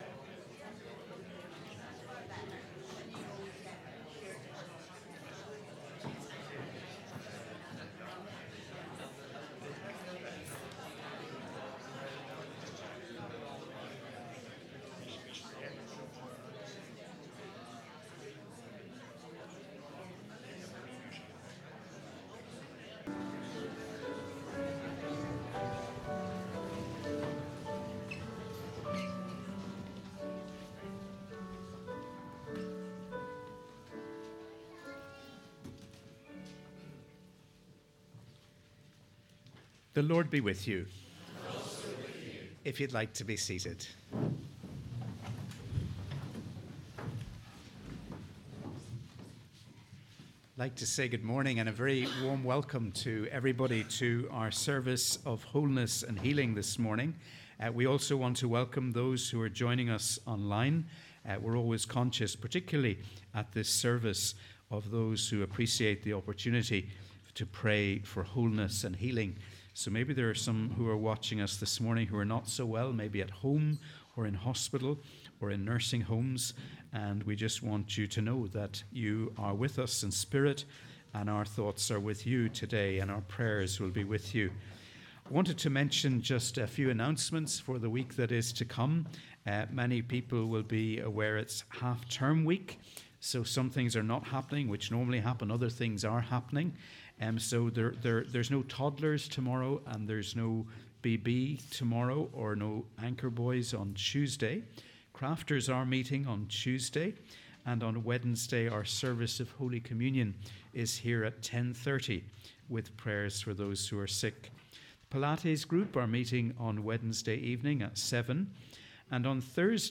We welcome you to our service of Wholeness & Healing.